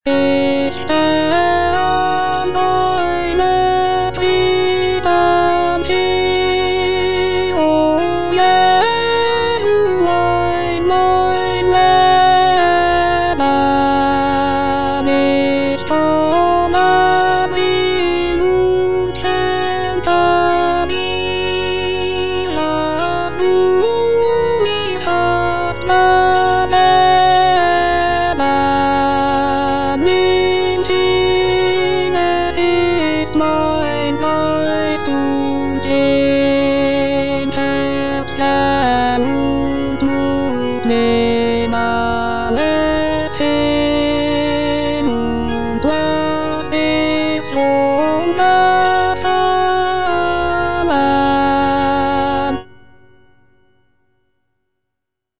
J.S. BACH - CHRISTMAS ORATORIO BWV248 - CANTATA NR. 6 (A = 415 Hz) 59 - Ich steh an deiner Krippen hier - Alto (Voice with metronome) Ads stop: